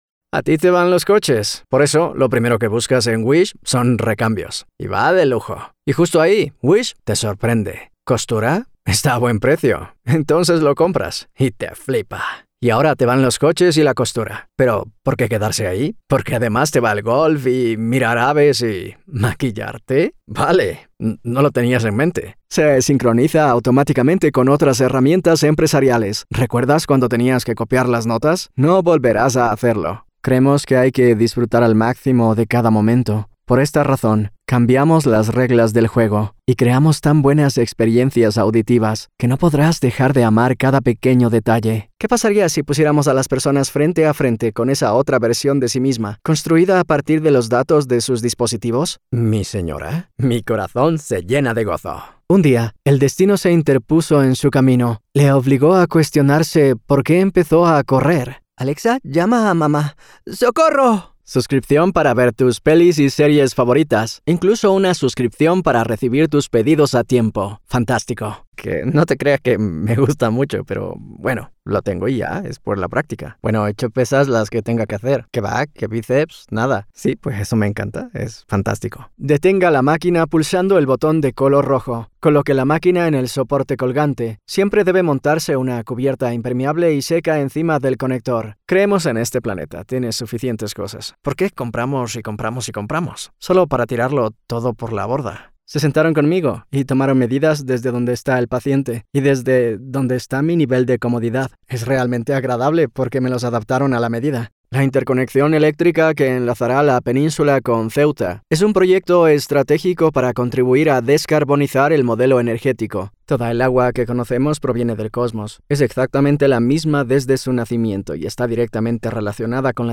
Demo
Has Own Studio
Spanish - Iberian
cool
friendly
smooth